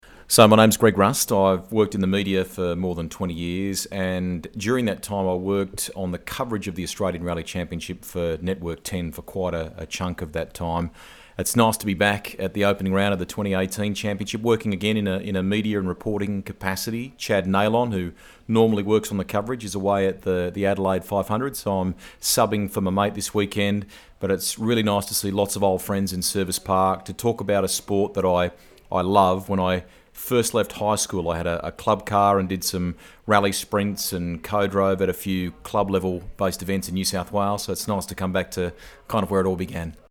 RADIO GRABS